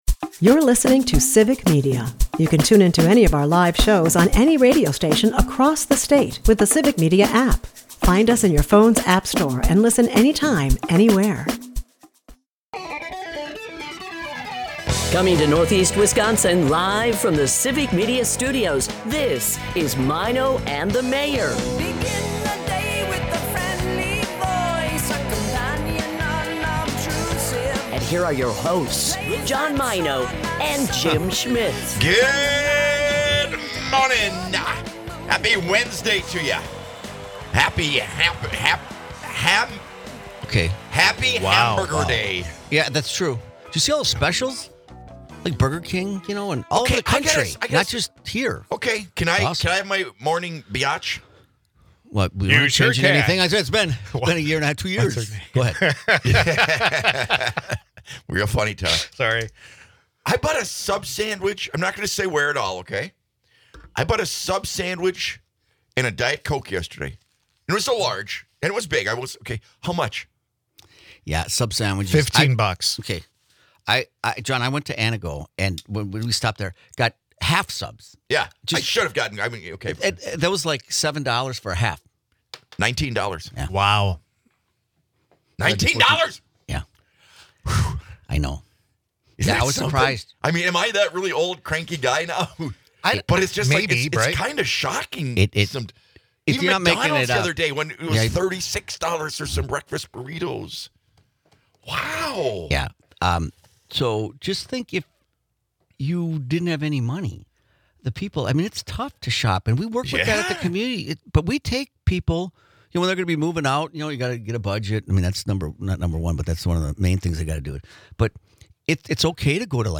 Maino and the Mayor is a part of the Civic Media radio network and airs Monday through Friday from 6-9 am on WGBW in Green Bay and on WISS in Appleton/Oshkosh.